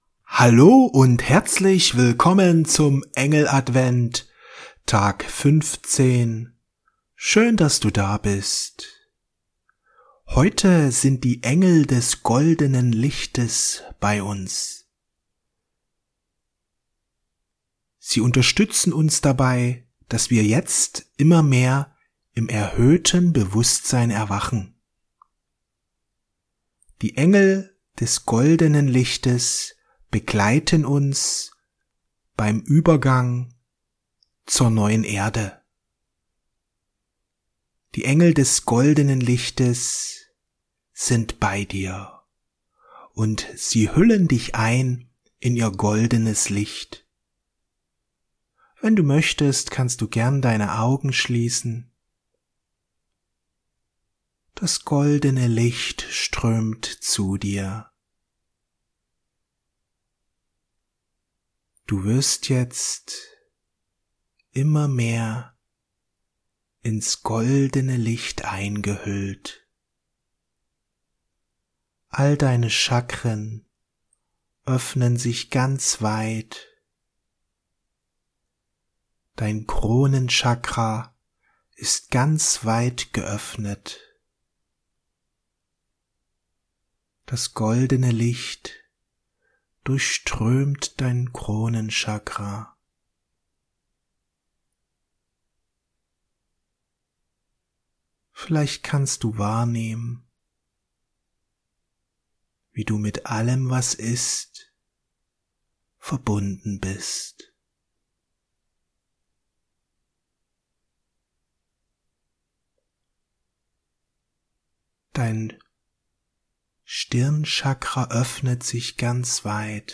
Die neue Erde und das goldene Zeitalter Meditation mit den Engeln des goldenen Lichts